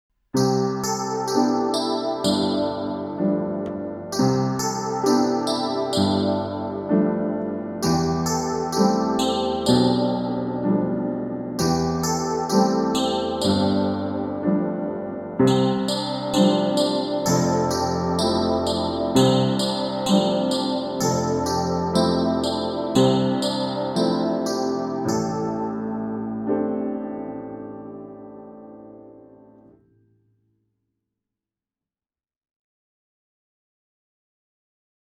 p39 n°6 à revoir – p40 n°2 : chant avec paroles et avec notes: 24 le miroir magique24 le miroir magique (5.89 Mo)